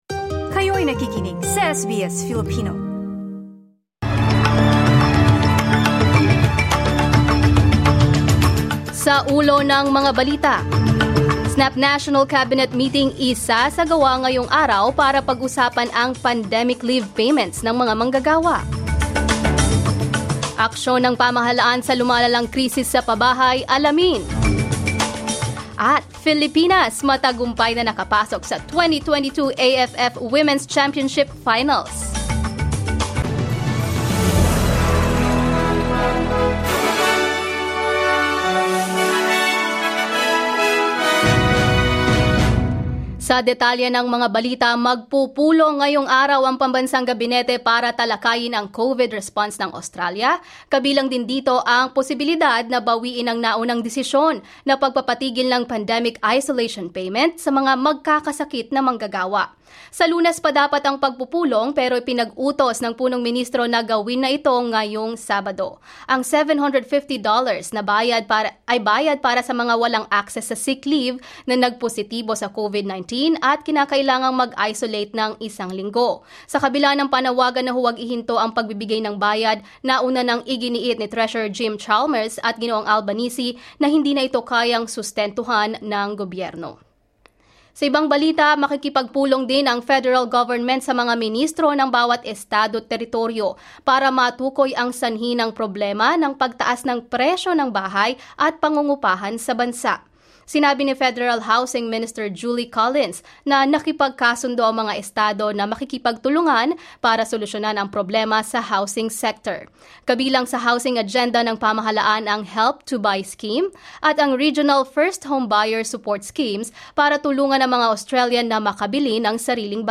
SBS News in Filipino, Saturday 16 July